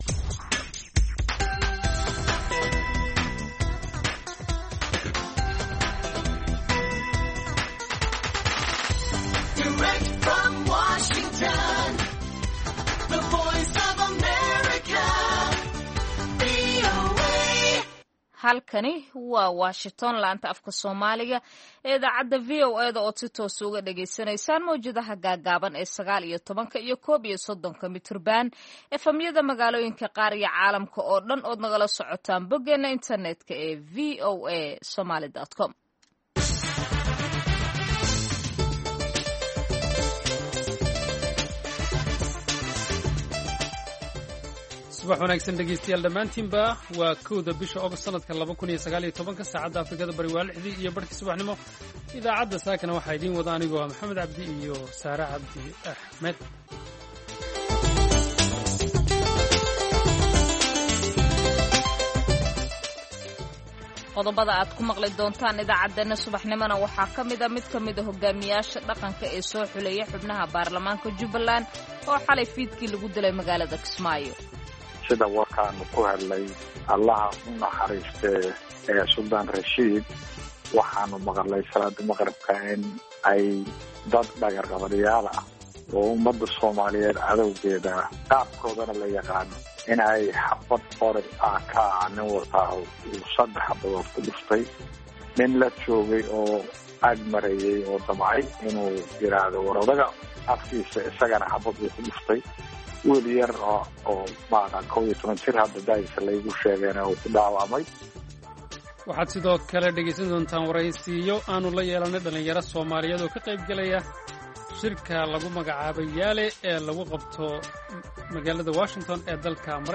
Idaacadda Subaxnimo ee Saaka iyo Caalamka waxaad ku maqashaa wararkii habeenimadii xalay ka dhacay Soomaaliya iyo waliba caalamka, barnaamijyo, wareysi xiiso leh, ciyaaraha, dhanbaallada dhagaystayaasha iyo waliba wargeysyada caalamku waxay saaka ku waabariisteen.